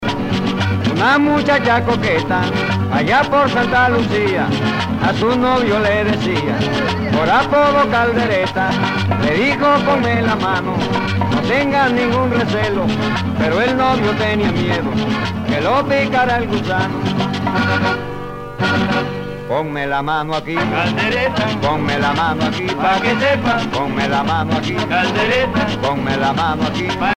danse : guaracha